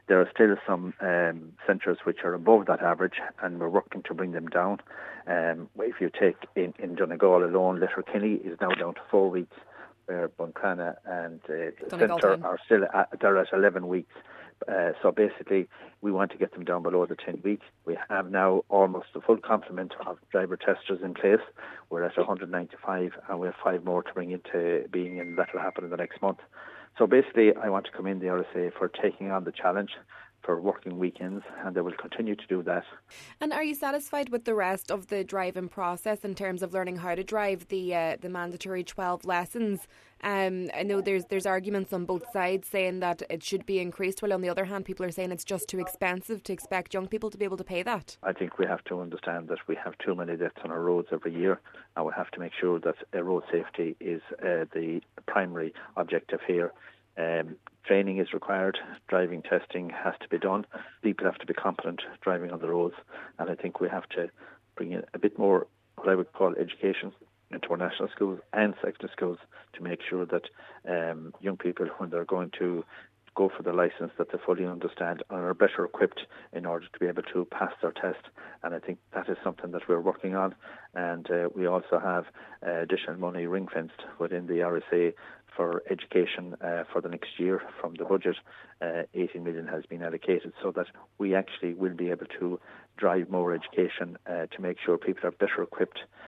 Minister Canney says efforts are continuously being made to bring the numbers down: